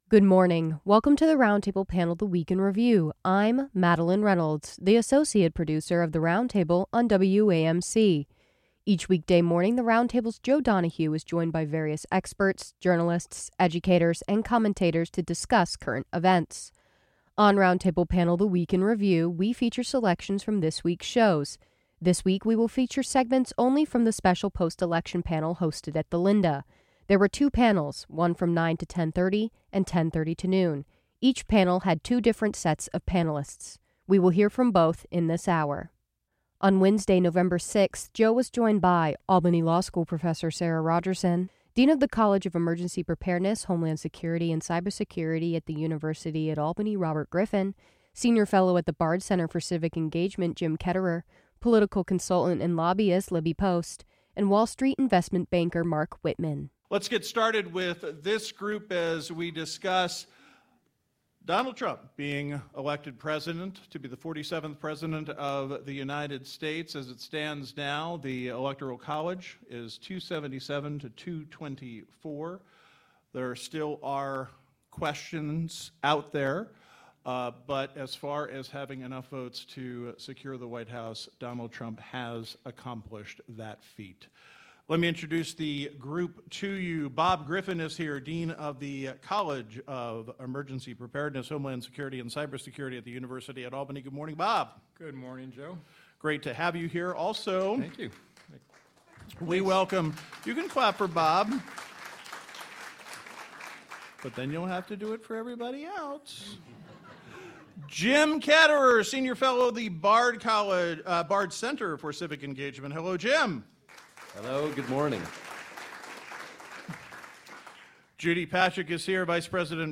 On Roundtable Panel: The Week in Review, we feature your favorite panelists discussing news items from the previous week.